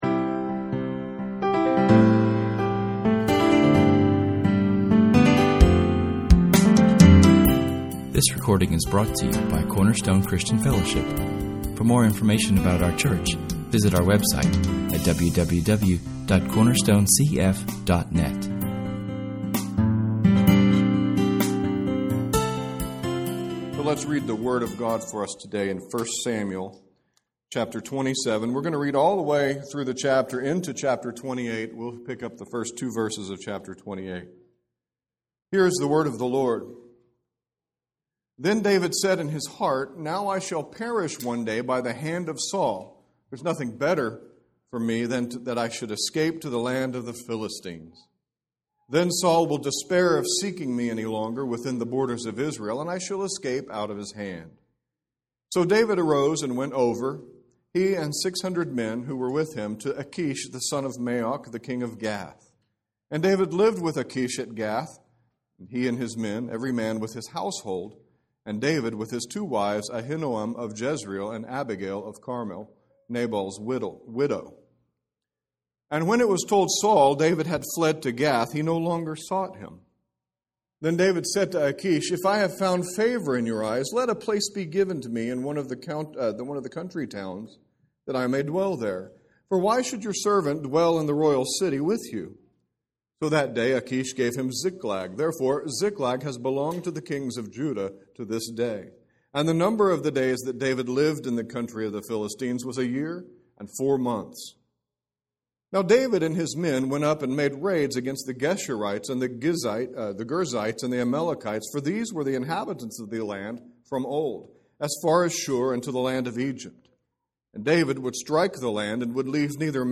Our sermon covers [esvignore]1 Samuel 27:1-28:2[/esvignore]. In this chapter we find a heart-breaking situation and a downfall of our hero, David. Through a series of poor faith and lapses of judgment, David yet again falls into trusting himself over God.